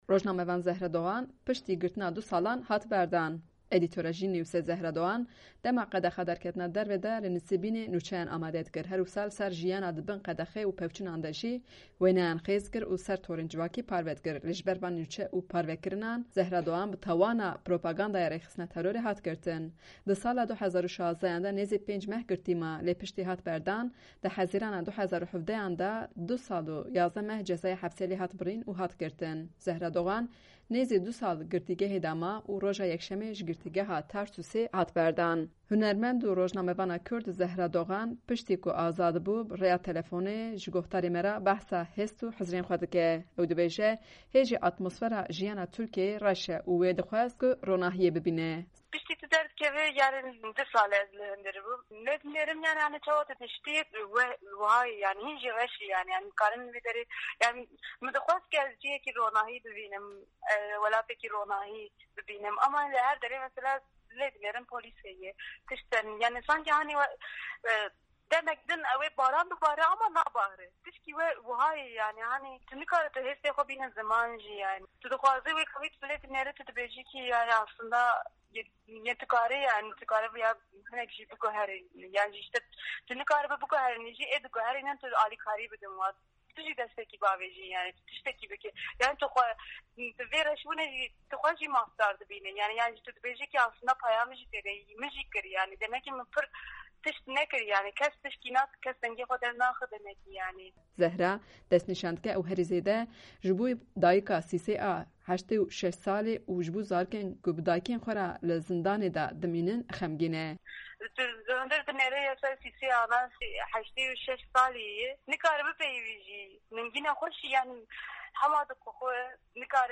Dogan piştî hat berdan bi rêya telefonê hizir û hestên xwe ji dengdarên Dengê Amerika re anî ziman.